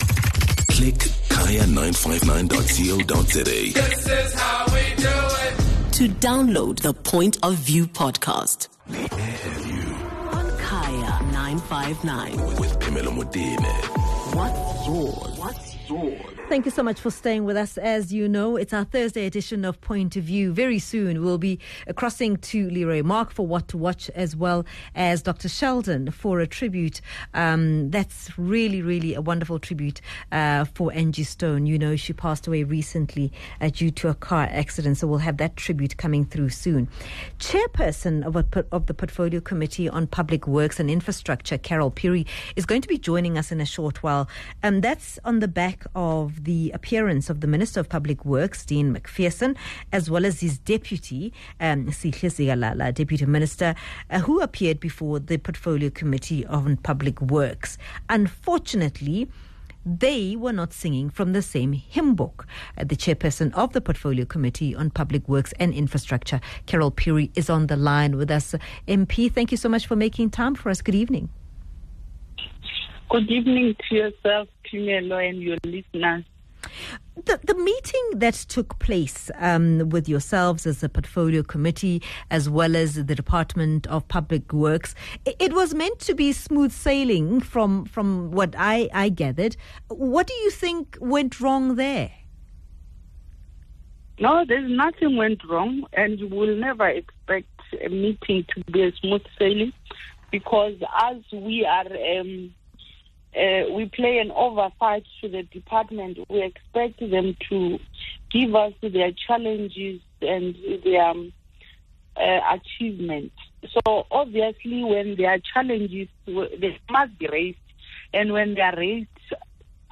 speaks to the Chairperson of the Portfolio Committee on Public Works and Infrastructure, Carol Phiri.